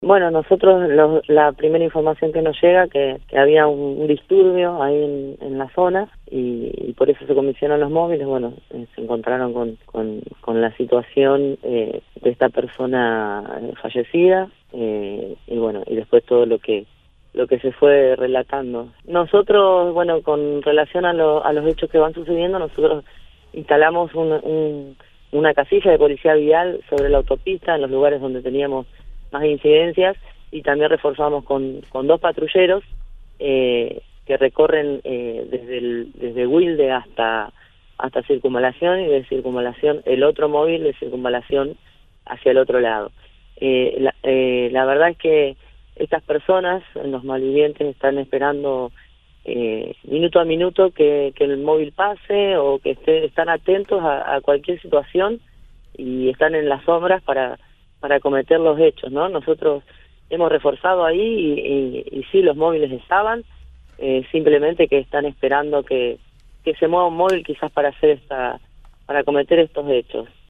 La jefa de la Policía de Santa Fe, Emilce Chimenti, habló en Cadena 3 Rosario y explicó cómo funciona la custodia policial en la zona de la autopista Rosario-Córdoba en la que este miércoles un hombre se resistió a un robo y mató a un delincuente, mientras que otra persona resultó herida de bala (su madre contó cómo fue el hecho).